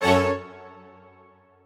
admin-leaf-alice-in-misanthrope/strings34_1_021.ogg at main